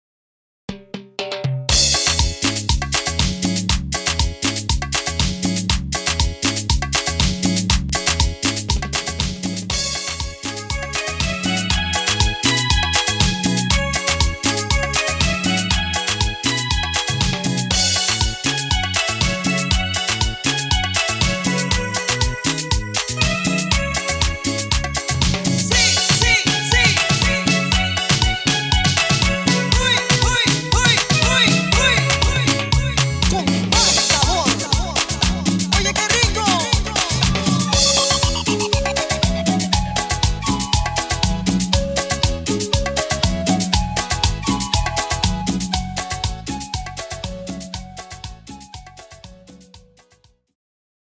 Etiqueta: Lambada